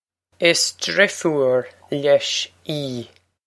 Pronunciation for how to say
Is drih-foor leh-sh ee.
This is an approximate phonetic pronunciation of the phrase.